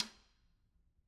Snare2-taps_v3_rr1_Sum.wav